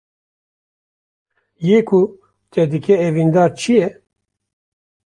Pronunciado como (IPA) /ɛviːnˈdɑːɾ/